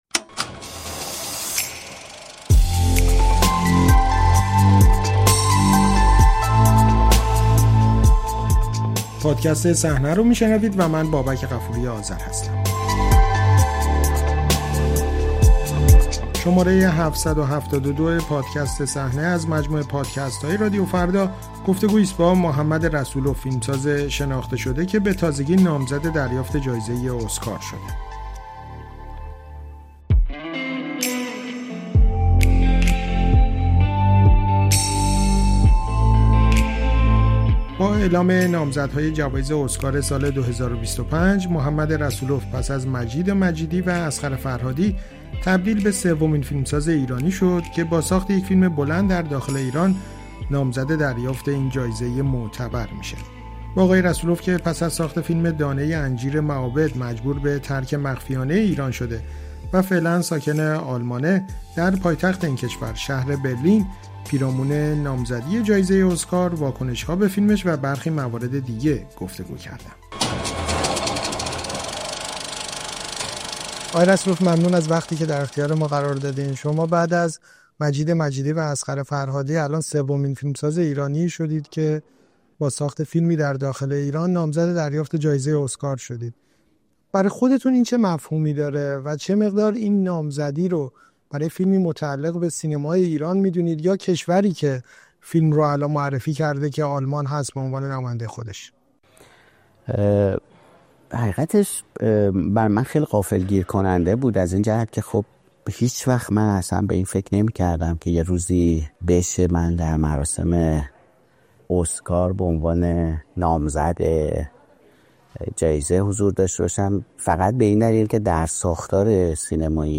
گفت‌وگو با محمد رسول‌اف پس از نامزدی در جوایز اسکار